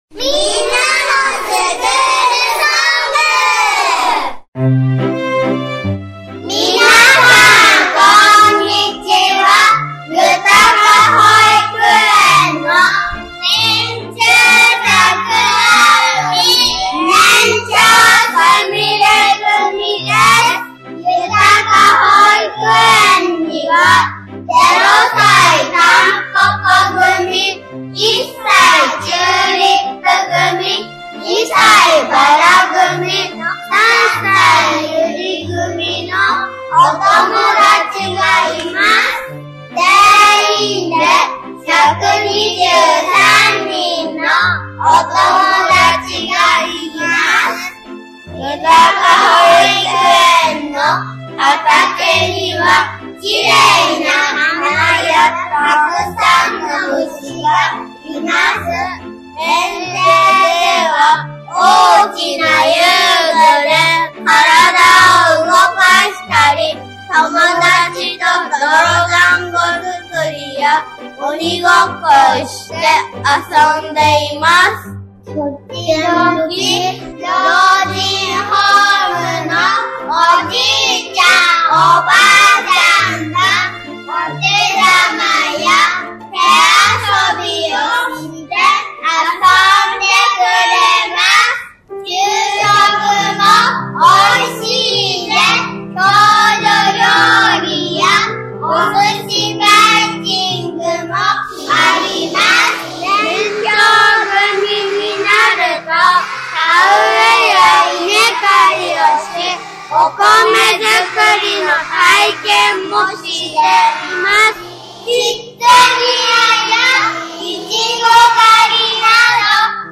今年度ゆたか保育園のみんなが、いせさきＦＭの「みんんあのスクールソング」に参加した音源です。